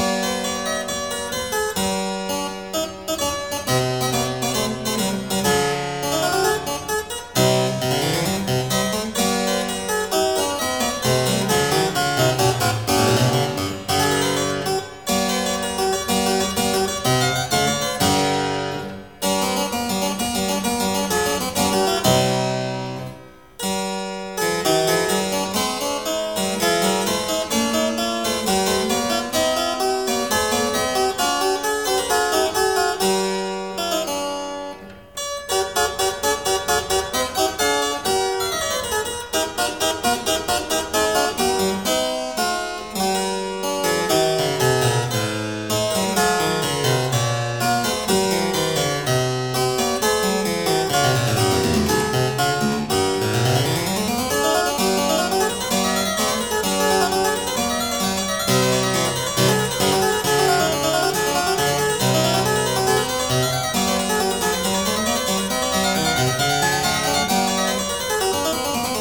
史威林克 / 大鍵琴作品
具有簡潔明朗的旋律美感，而他在教育上的努力也影響了德國巴洛可音樂的發展。
而這也讓她在演奏這些作品時能深入到大鍵琴機能的內在，重現巴洛可的活潑精神。